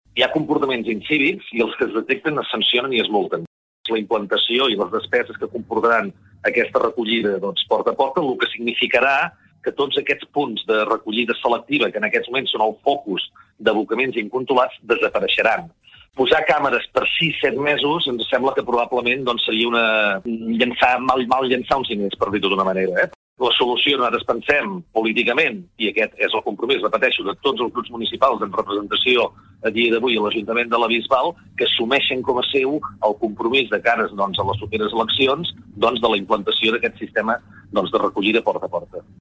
Entrevistes SupermatíLa Bisbal d'Empordà